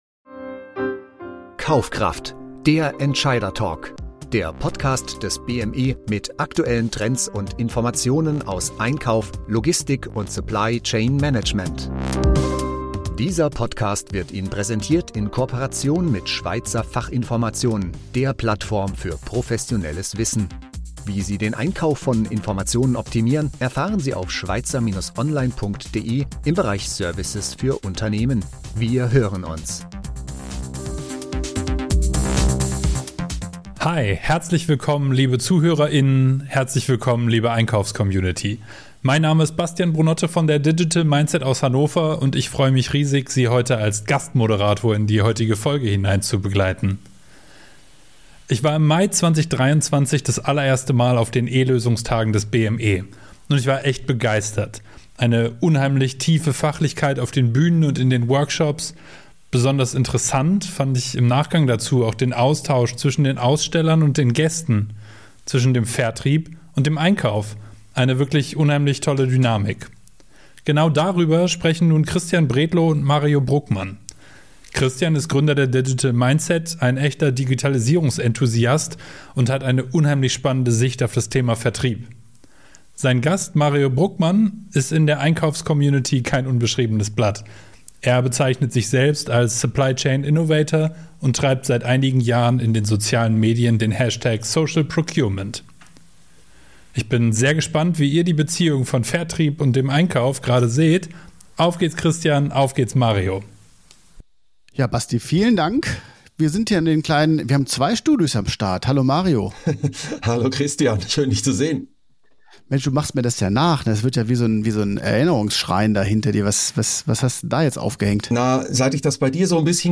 Im Vorfeld der bevorstehenden eLÖSUNGSTAGE 2024 möchten wir Ihnen ein bereits im letzten Jahr aufgezeichnetes Interview vorstellen.